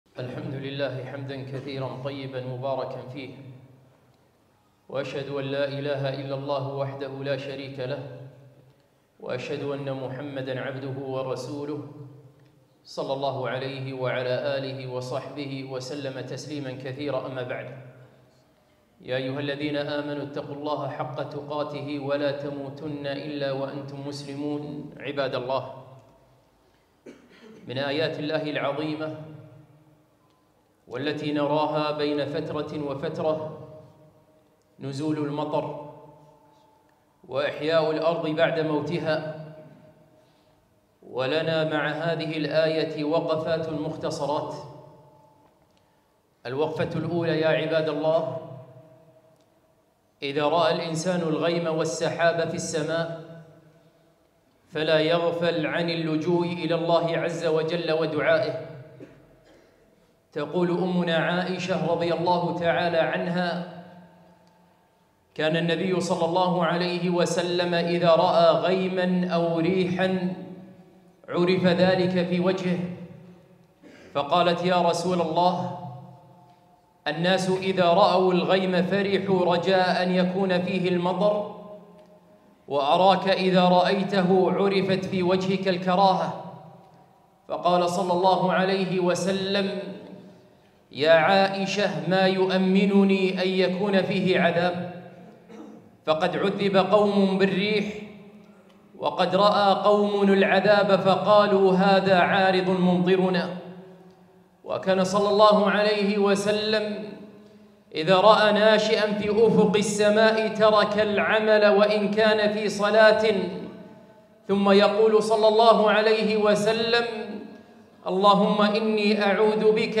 خطبة - وقفات متعلقة بنزول المطر